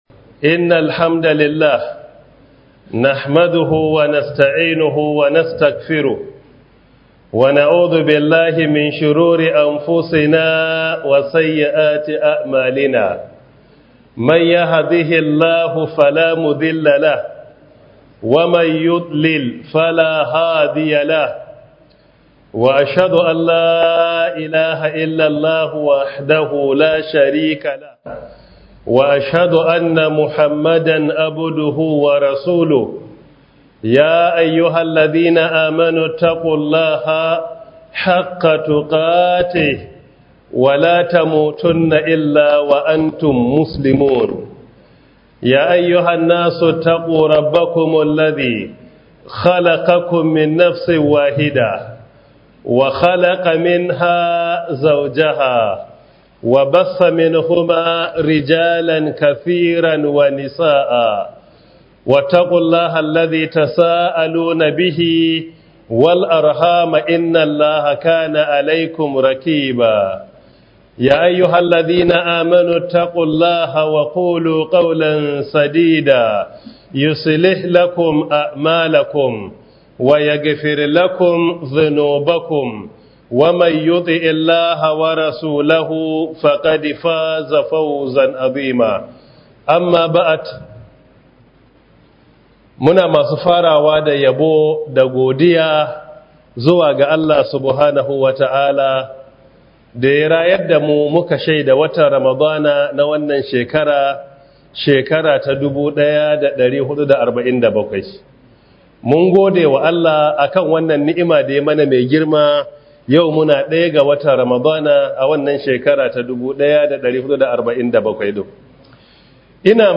001 Ramadan Tafseer 2025 Prof. Isa Ali Pantami